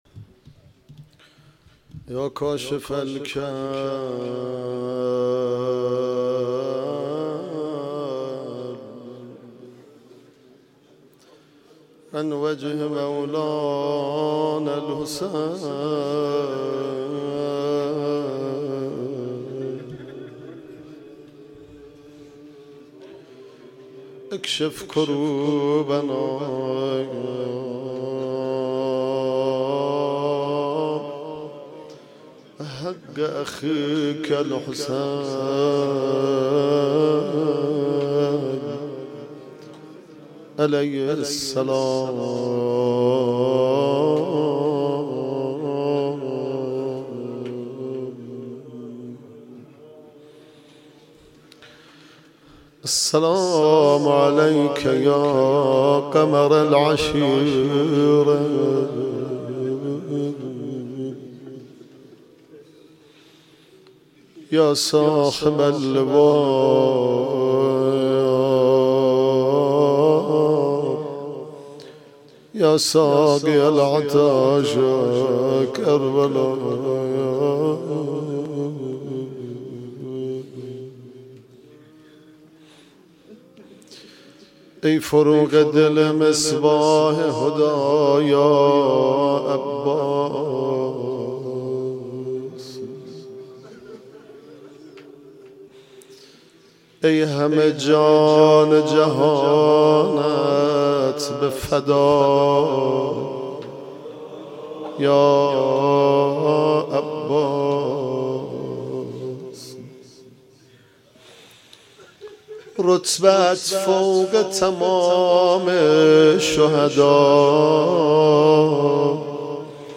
سخنرانی
مرثیه سرایی